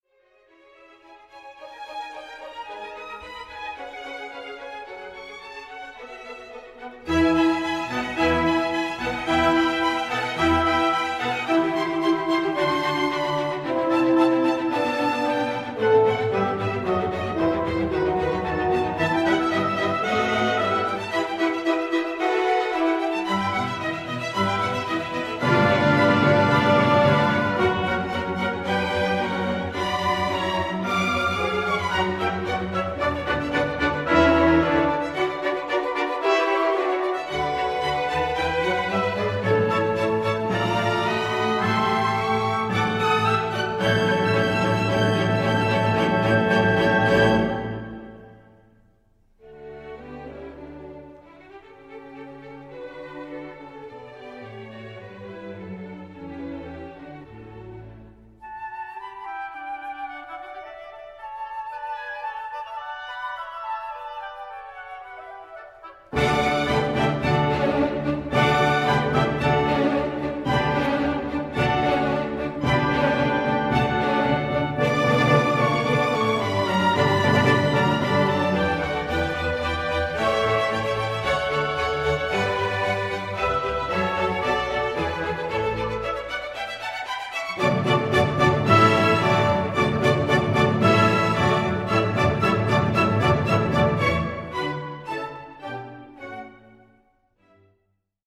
Un bajo de gaita precede y sostiene un tema de carácter aparentemente popular que algunos estudiosos han identificado como inglés y otros como croata, pero que no se descarta que surgiera de la propia imaginación del músico.
3-Sinfonia-104-Londres.mp3